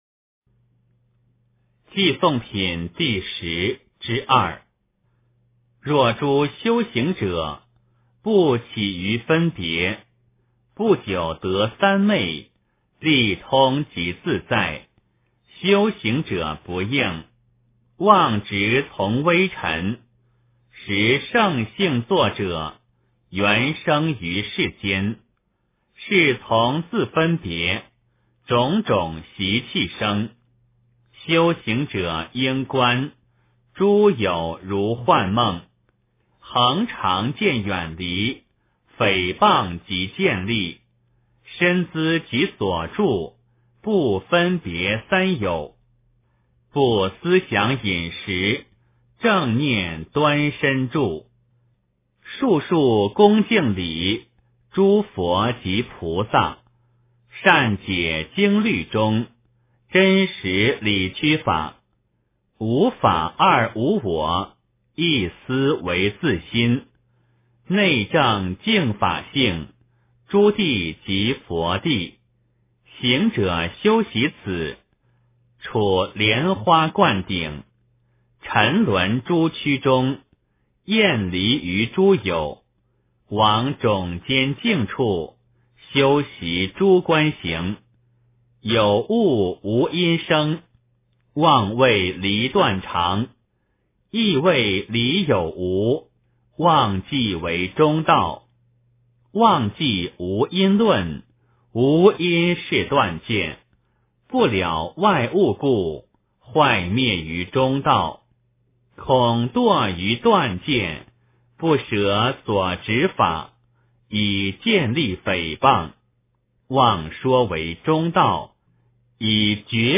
楞伽经（偈颂品第十之二） - 诵经 - 云佛论坛